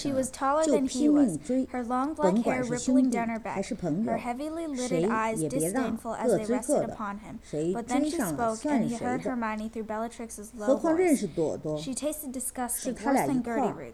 Wre also include a couple of real recordings examples, each of which consists of a two-speaker mixture recorded in two channels.
Real Recording 1 (regular office, cheap Skype microphones about $13 apiece, moderately echoic).